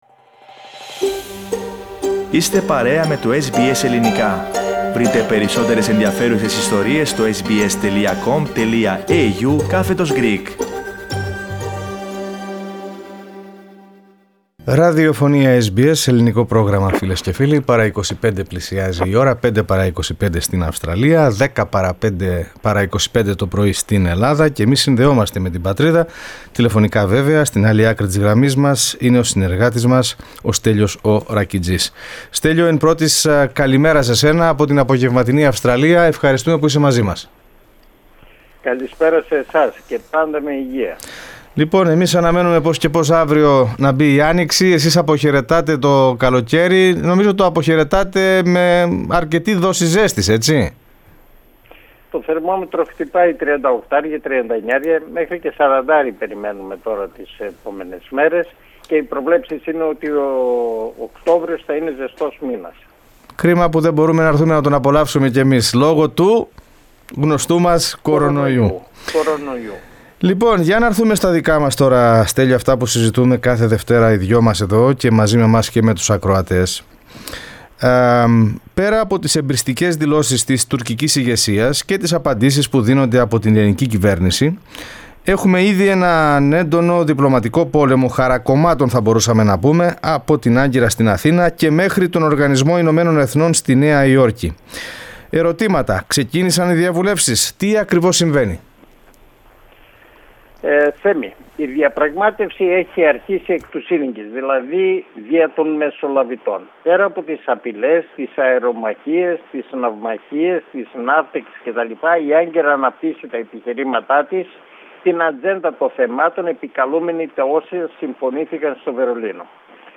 Τα Ελληνοτουρκικά στο επίκεντρο της εβδομαδιαία ανταπόκρισης από την Αθήνα (31.8.2020)